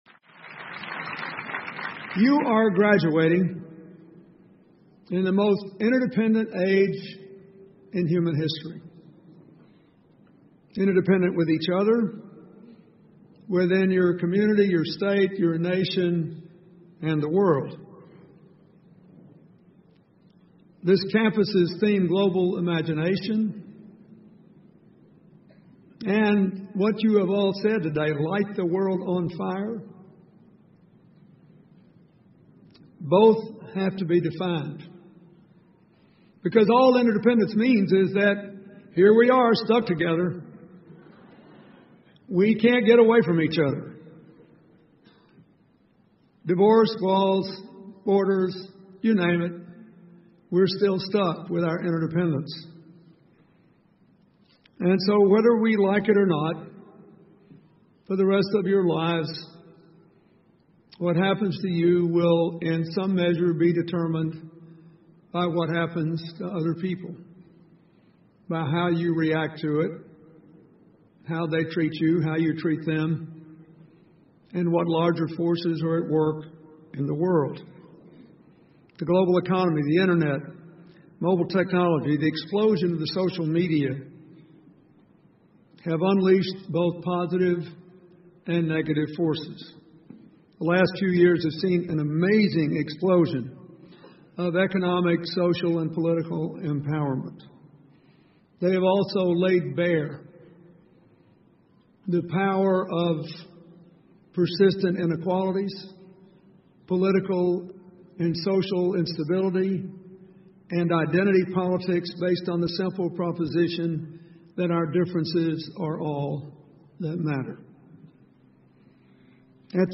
英文演讲录 比尔·克林顿：点燃世界(1) 听力文件下载—在线英语听力室